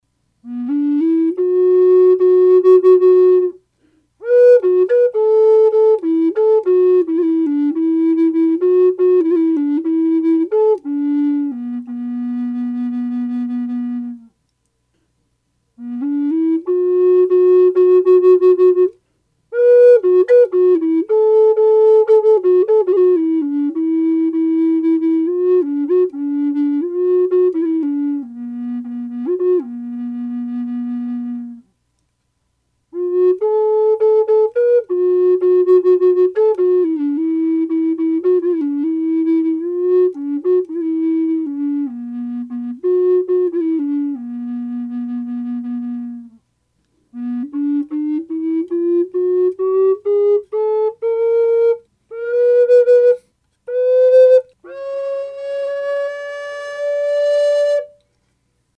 Sound sample of  this Flute short melody + 16 notes scale  without reverb/raw unprocessed